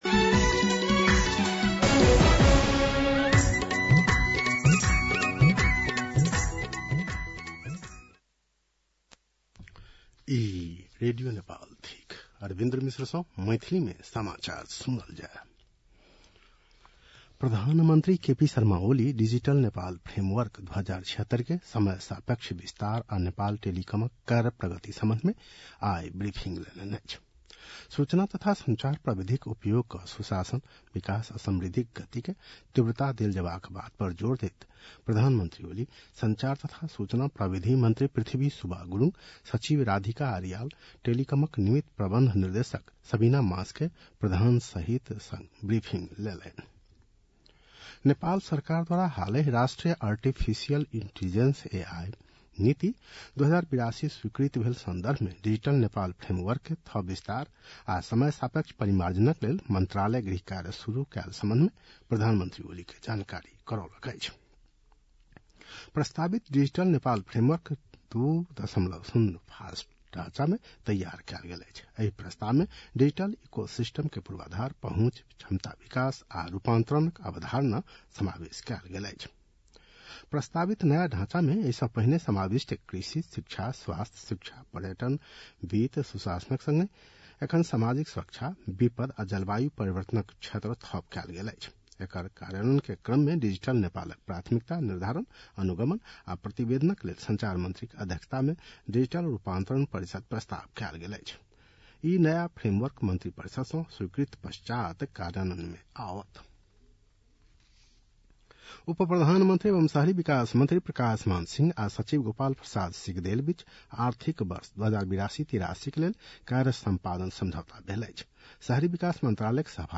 मैथिली भाषामा समाचार : २ भदौ , २०८२
6.-pm-maithali-news-1-4.mp3